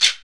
Index of /90_sSampleCDs/Roland L-CD701/PRC_Latin 2/PRC_Shakers